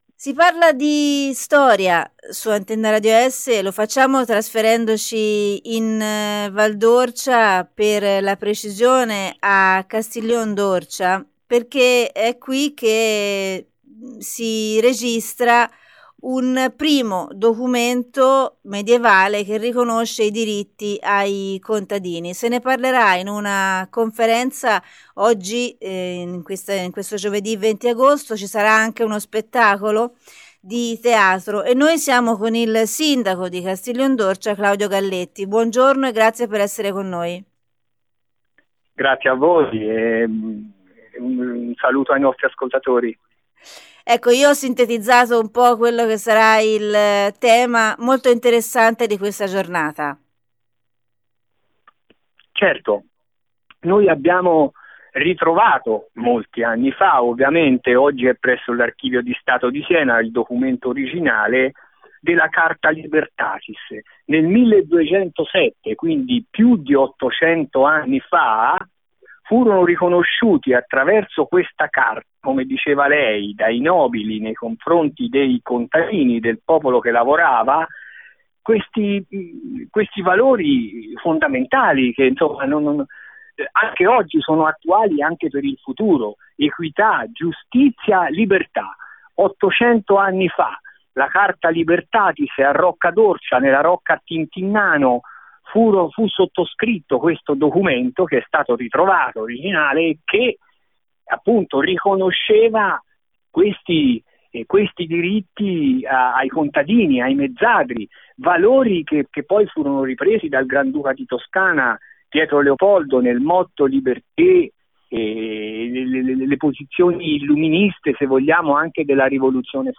ASCOLTA L’INTERVISTA DEL SINDACO CLAUDIO GALLETTI